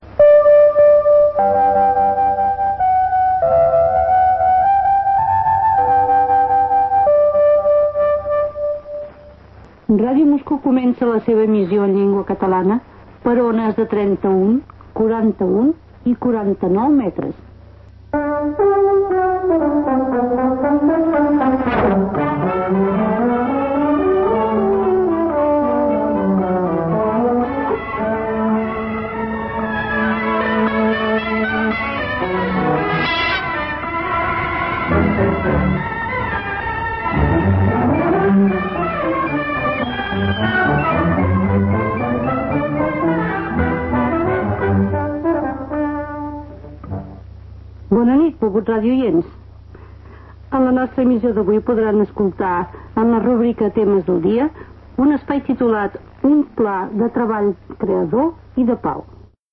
Sintonia, inici del programa en català amb la "Sardana de les monges" i contingut de l'emissió.